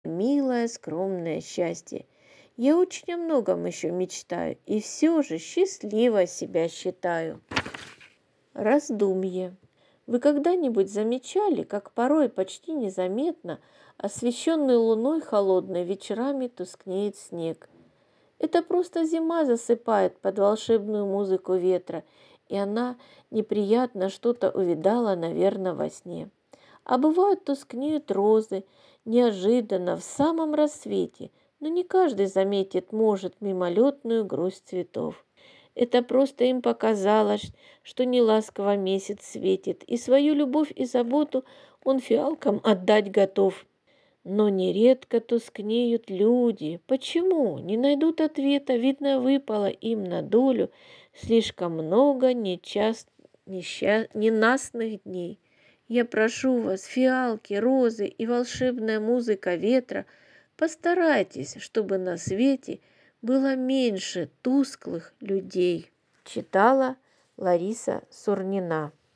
К Международному дню инвалидов в Ростовском пункте выдачи библиотеки для слепых была подготовлена аудиобеседа «